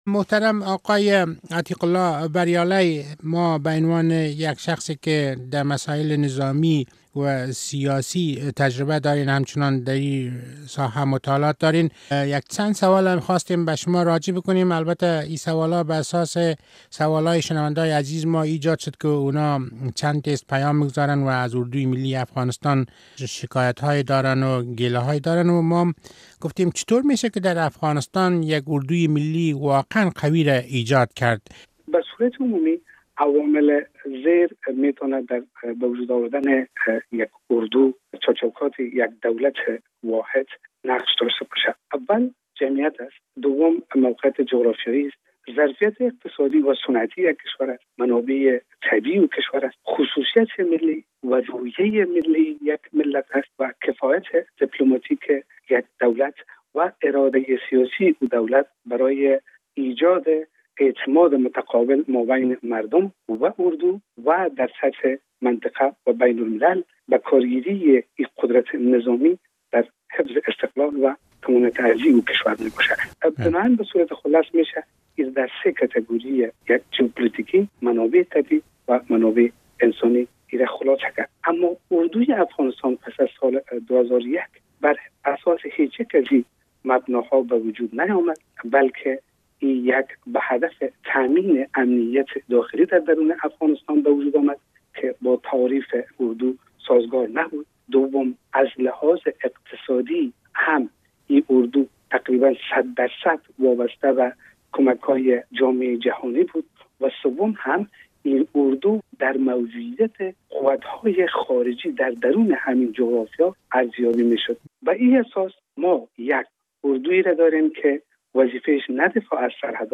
مصاحبه با عتیق الله بریالی در مورد شرایط ایجاد یک اردوی قوی و ملی